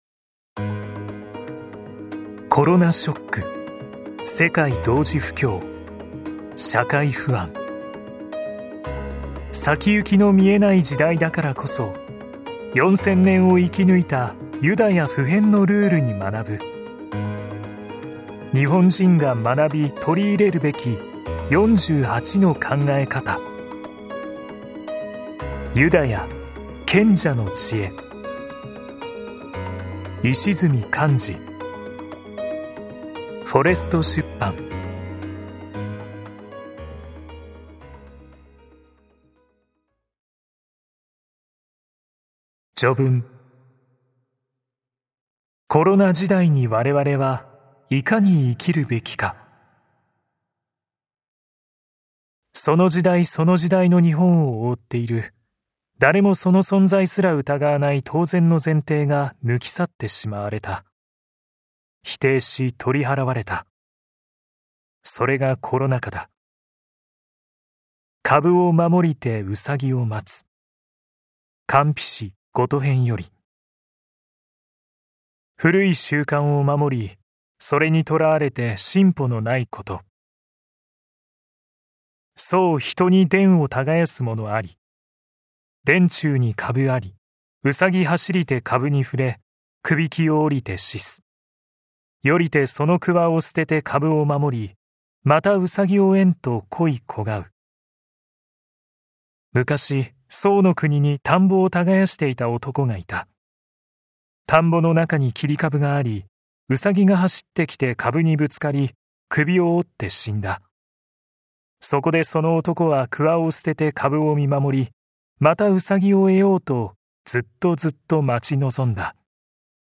[オーディオブック] ユダヤ 賢者の知恵